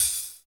39 HAT 4  -L.wav